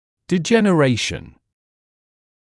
[dɪˌʤenə’reɪʃn][диˌджэнэ’рэйшн]дегенерация; перерождение; деградация